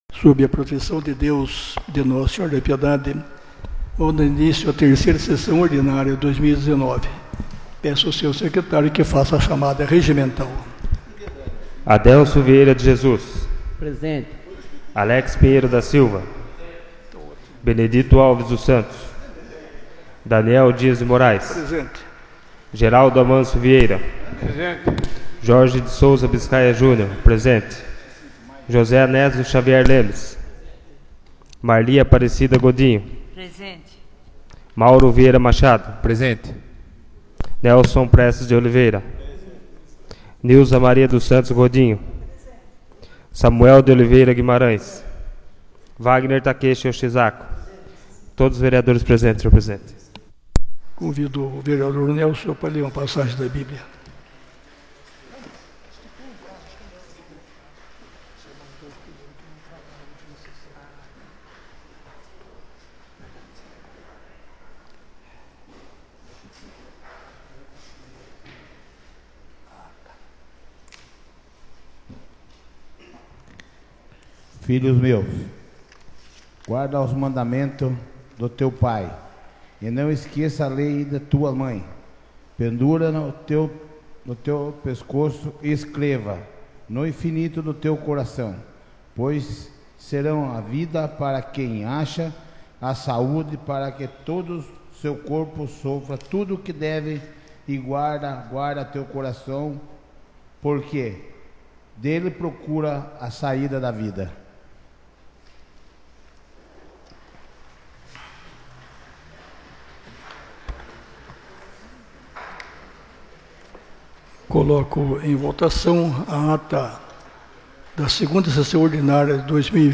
3ª Sessão Ordinária de 2020